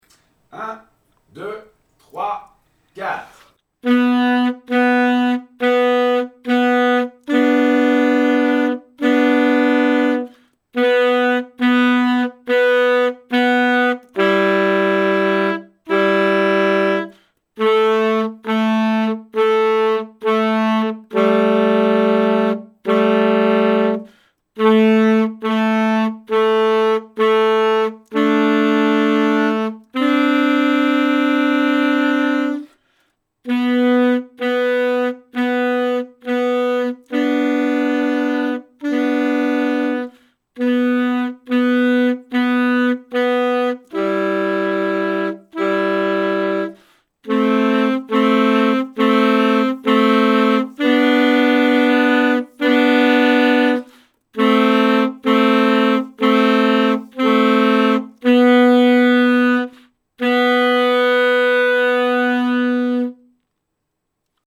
Sans le hautbois